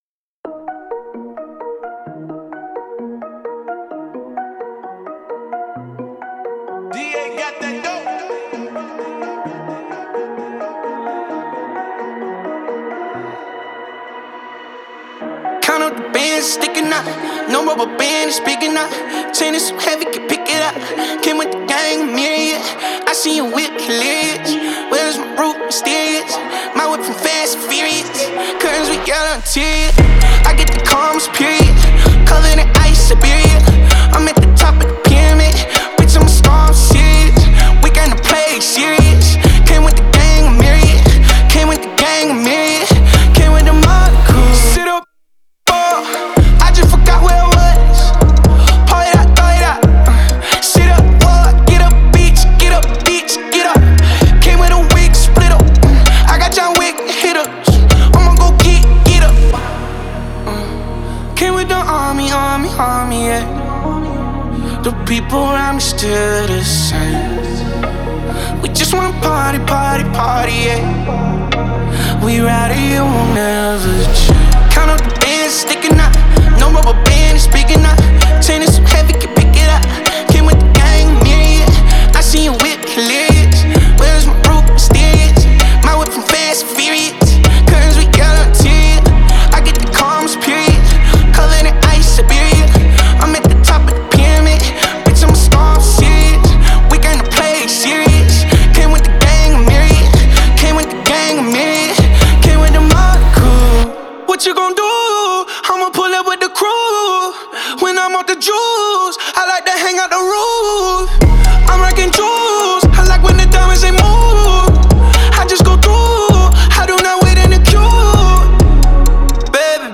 которая сочетает в себе элементы хард-рока и глэм-рока.
мощные гитары, запоминающийся припев и харизматичный вокал.